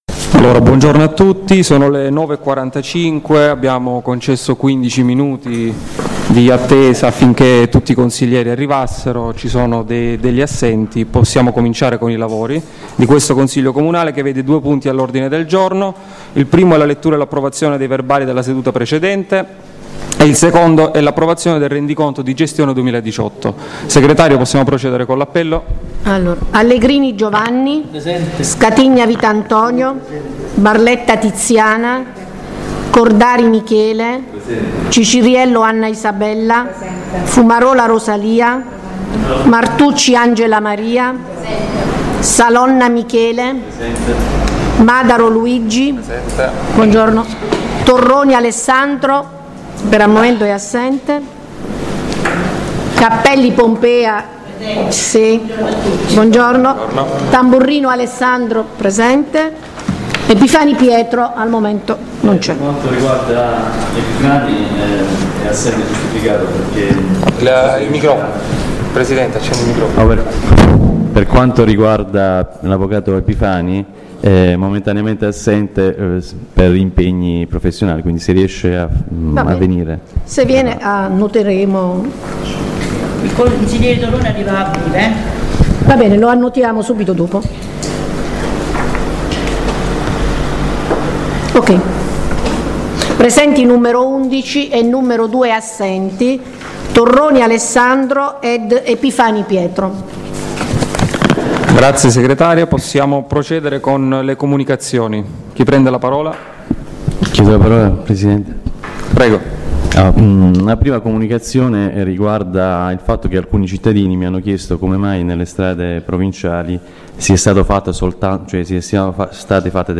La registrazione audio del Consiglio Comunale di San Michele Salentino del 30/05/2019